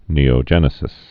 (nēō-jĕnĭ-sĭs)